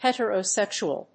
音節het・er・o・sex・u・al 発音記号・読み方
/hèṭəroʊsékʃuəl(米国英語), ˌhetɜ:əʊˈseˌkʃu:ʌl(英国英語)/